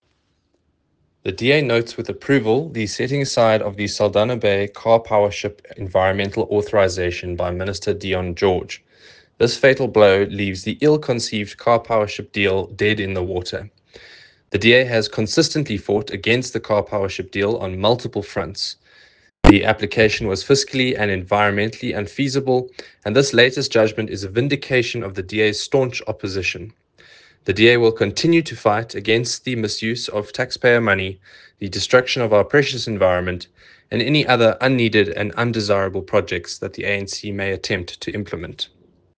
soundbite by Andrew de Blocq MP.